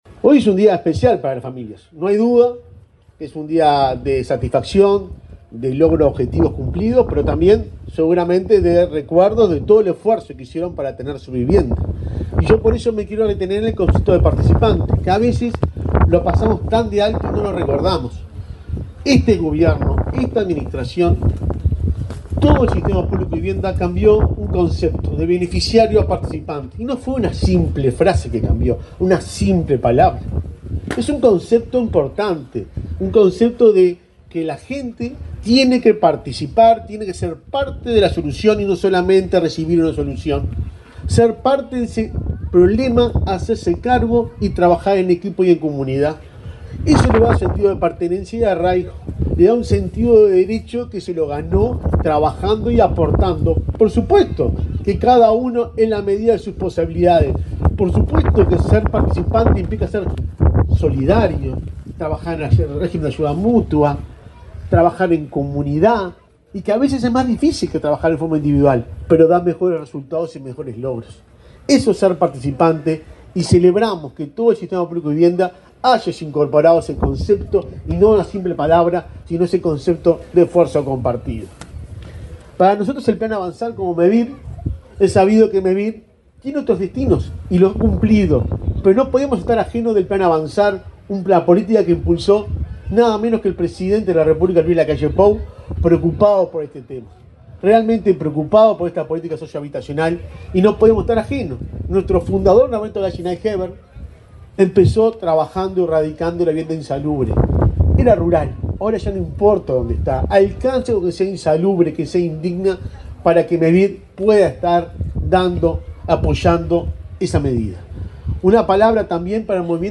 Palabra de autoridades en acto de entrega de viviendas en Flores
El presidente de Mevir, Juan Pablo Delgado, y el ministro de Vivienda, Raúl Lozano, participaron en la inauguración de 32 viviendas del Plan Avanzar,